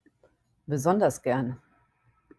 besonders gern (be-SON-ders gern)